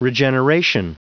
Prononciation du mot regeneration en anglais (fichier audio)
regeneration.wav